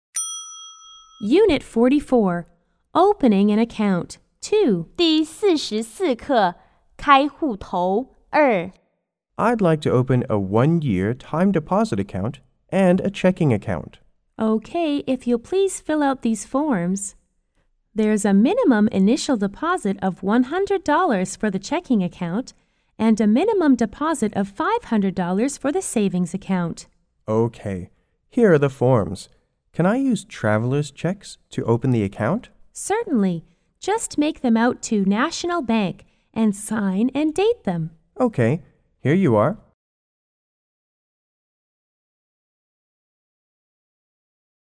C= Customer S= Salesperson